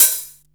Index of /90_sSampleCDs/USB Soundscan vol.20 - Fresh Disco House I [AKAI] 1CD/Partition D/01-HH OPEN